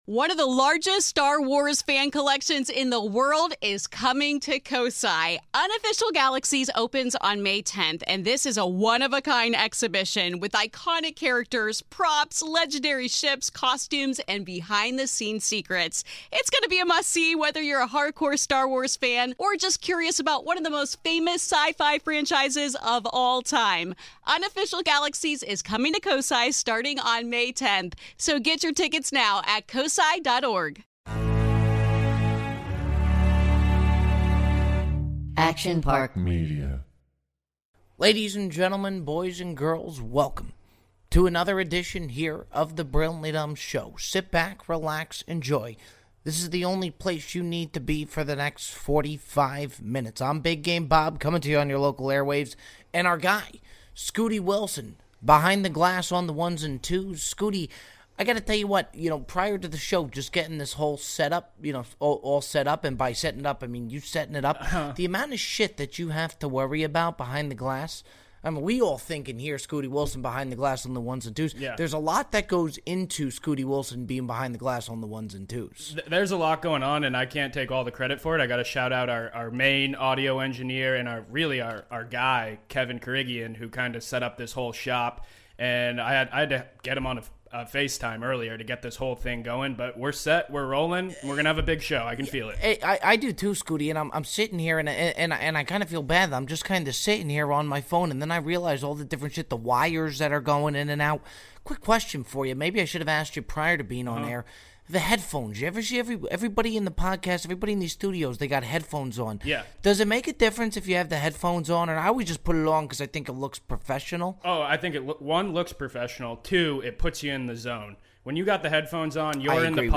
Listener voicemails to end the show.